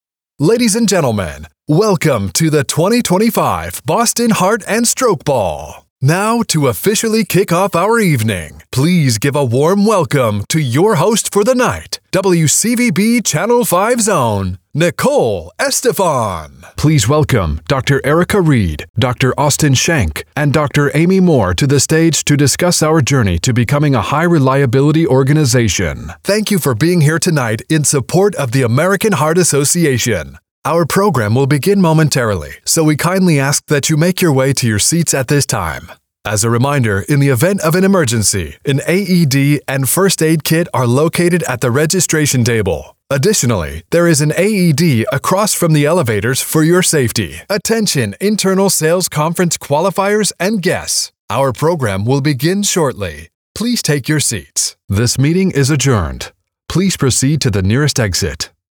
A deep, rich, gritty male voice talent for commercials and narration
VOG Demo
Event VOG Demo_2025.mp3